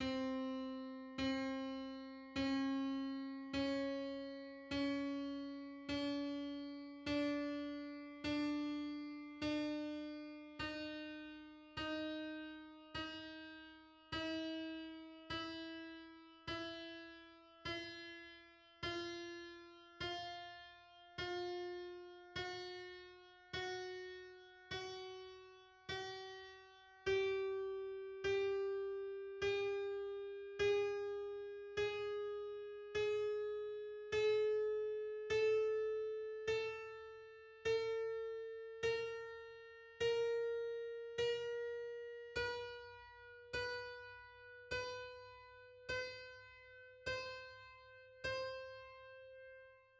41-tet_scale_on_C.mid.mp3